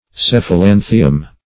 Search Result for " cephalanthium" : The Collaborative International Dictionary of English v.0.48: Cephalanthium \Ceph`a*lan"thi*um\, n. [NL., fr. Gr.